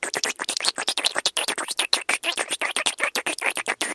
Мультяшный вариант звук сосания груди